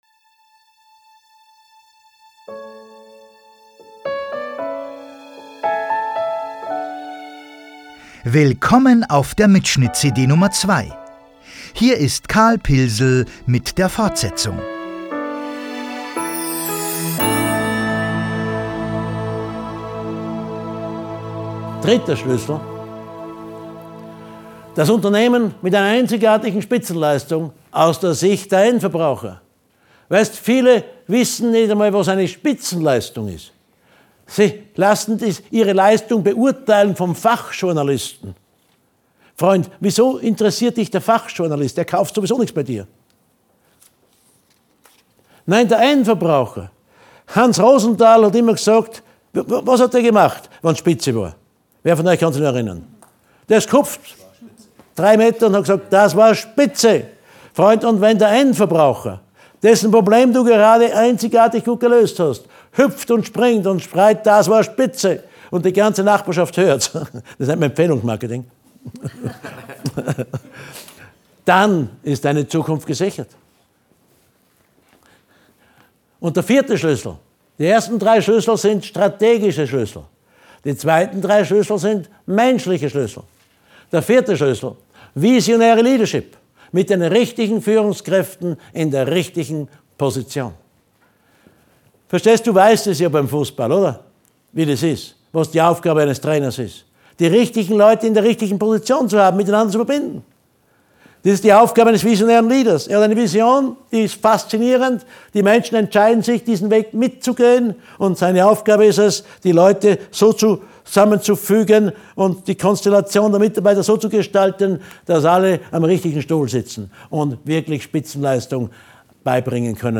CD 2: »Das Pilsl-M-Prinzip«, LIVE-Mitschnitt Teil 05